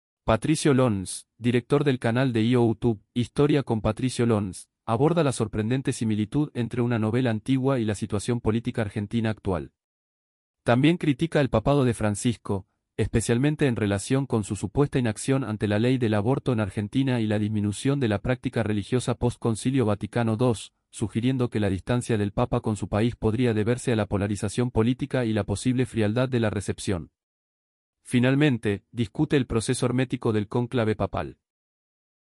¿De qué trata esta entrevista?